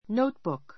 nóutbuk ノ ウ ト ブ ク